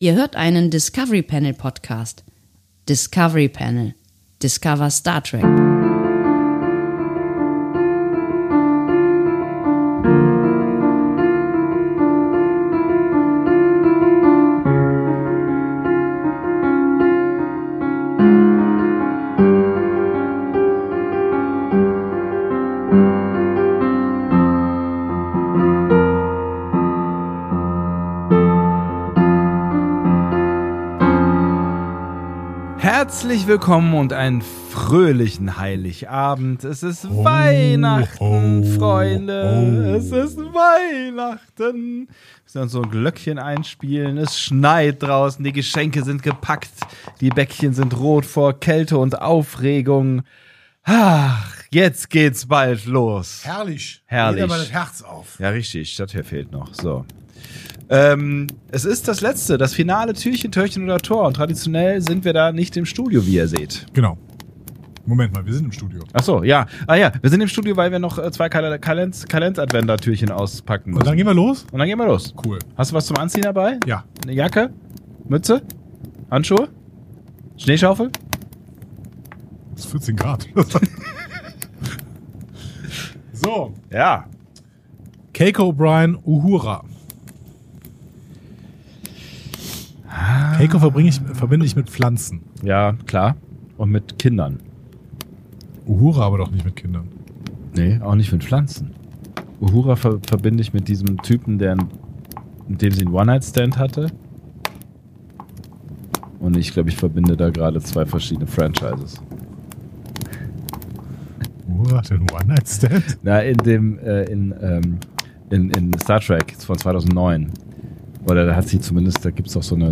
Beschreibung vor 3 Monaten Türchen 24 – Heiligabend im Discovery Panel Adventskalender! Wir starten im Weihnachtsstudio (ja, wirklich Studio – auch wenn’s sich anfühlt wie ein Holodeck, das „Gemütlichkeit“ nur vom Hörensagen kennt) und enden auf dem Weihnachtsmarkt.